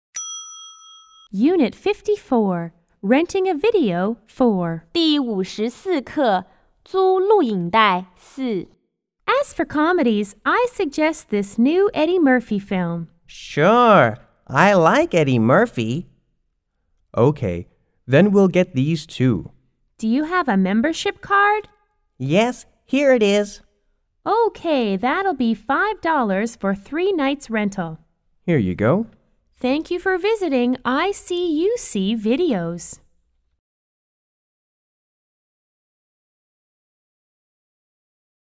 S= Salesperson C= Customer 1 C= Customer 2